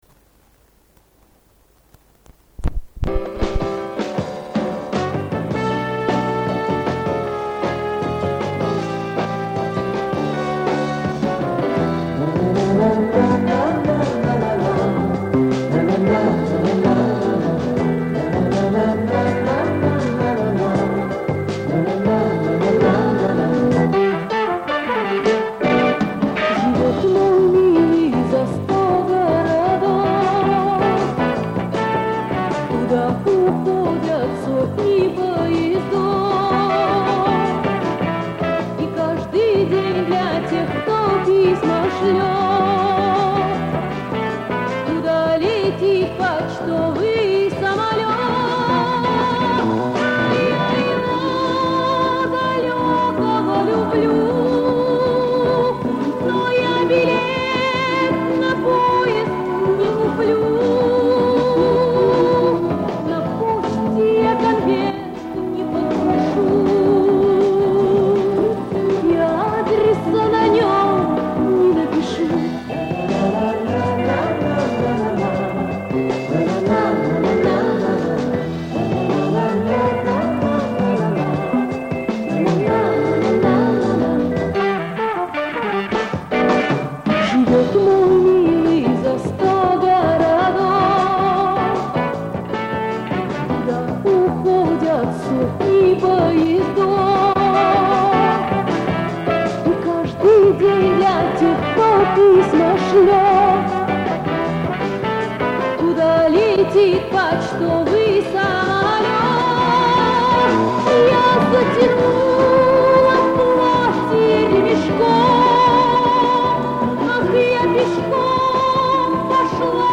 бас-гитаре